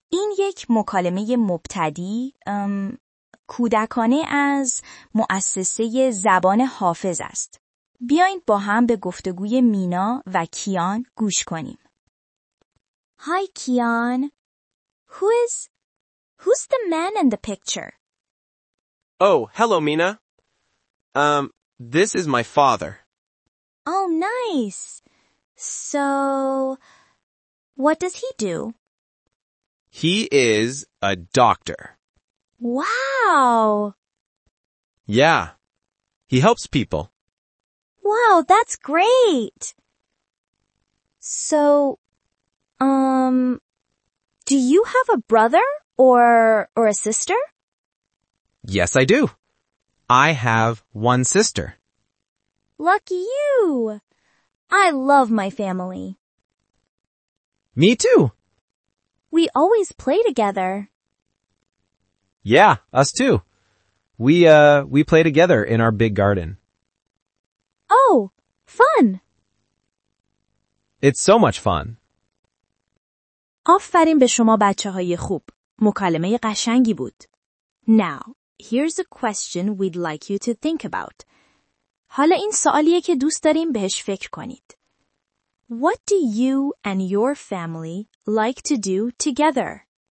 kids-converstaion.mp3